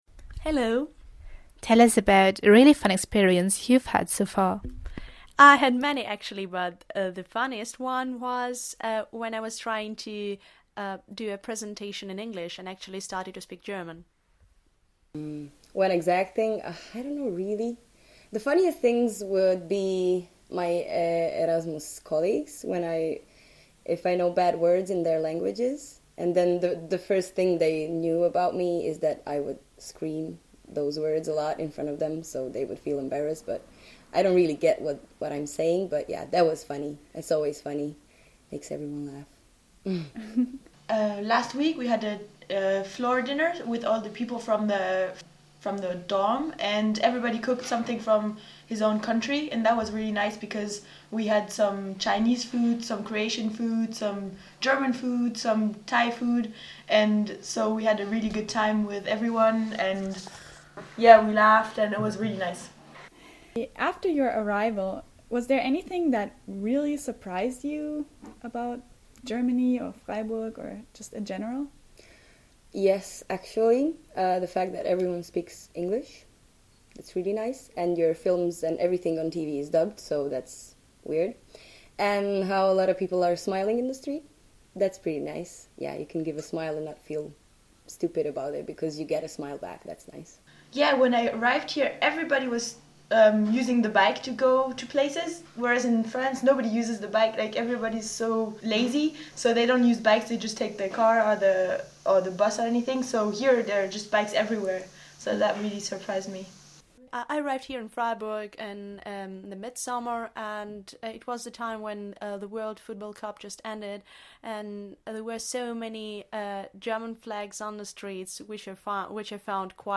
3 Internationals, 3 interviews